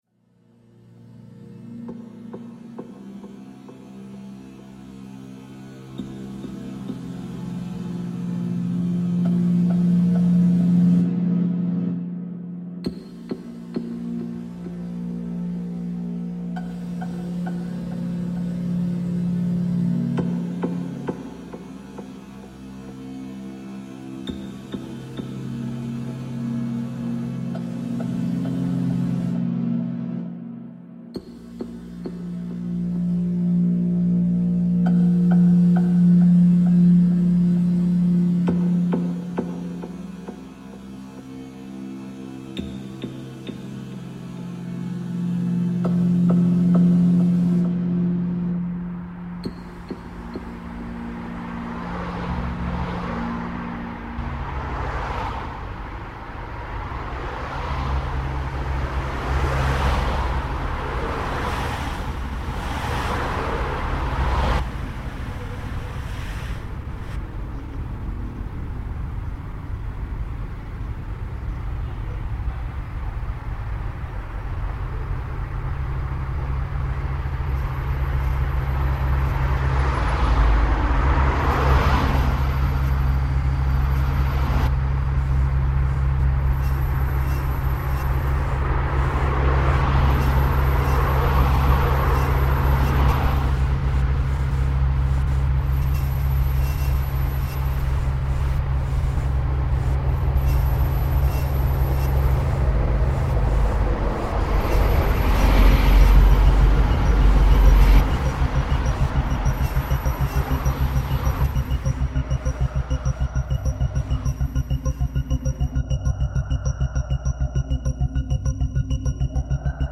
artist residency at bow church, london 2012 - 2014
light and sound are manipulated from the surrounding environment of the church and relayed within the building to create a series of events which interpret and transform the space and how it is experienced.
live sound was relayed from both sides of the church, focusing on the movement of the busy main road casting sound into the interior, and resulting in an environment that is shaped by the acoustics of the architecture of church and the fluctuating patterns of the outside world.
this evolving, immersive work became the starting point for a series of performances responding to the patterns of the traffic, which also included a church bell ringing and improvisation piece using the the church organ.
Bow Church Residency 3 min edit.mp3